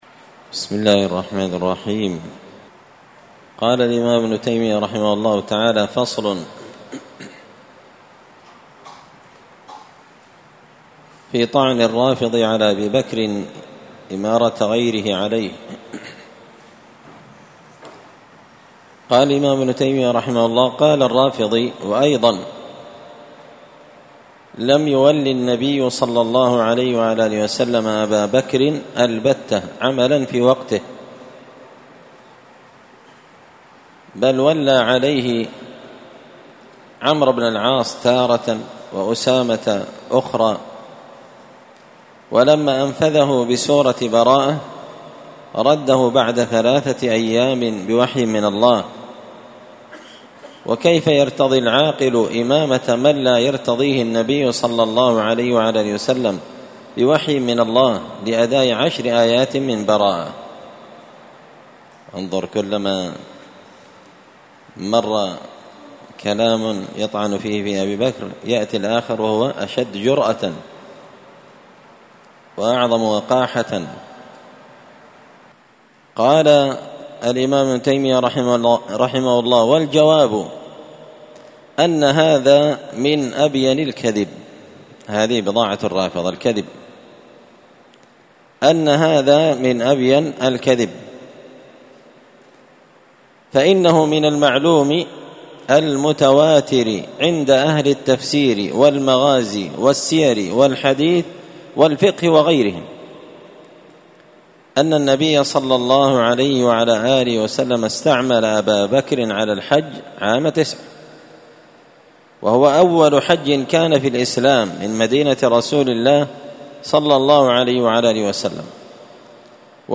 مختصر منهاج السنة النبوية لشيخ الإسلام ابن تيمية الحراني رحمة الله عليه ـ الدرس الرابع بعد المائة (104) فصل في طعن الرافضي على أبي بكر إمامة غيره عليه